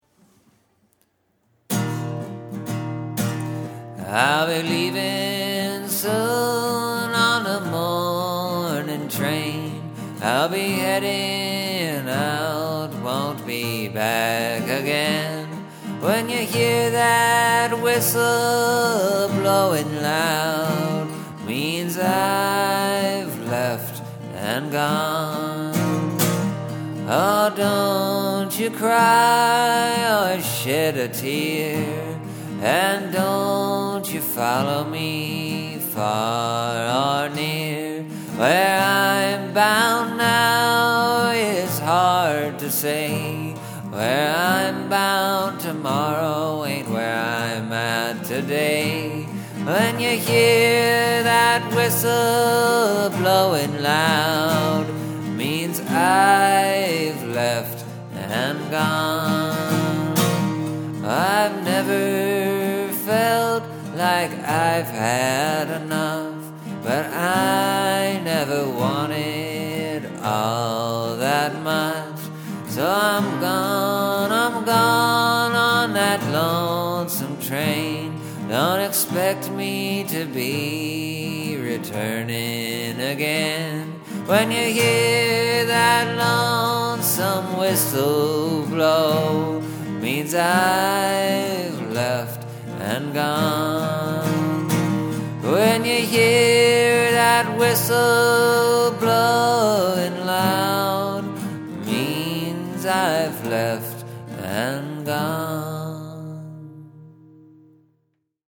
The other song I was working on was in D with the capo at 3, so I was already there. And it was in 3/4 time.
It’s kind of a plodding song, but I think it gets the point across.